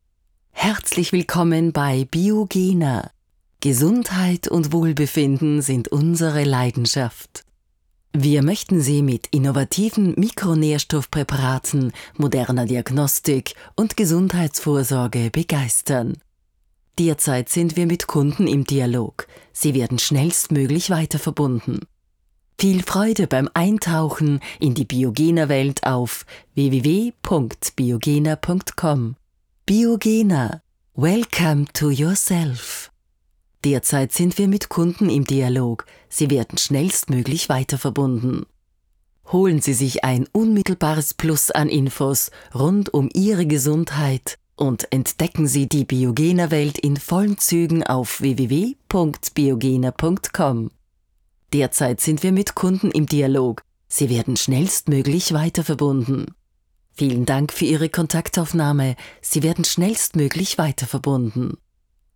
Mittel plus (35-65)
Wienerisch
Wait Loop (Warteschleife)
Austrian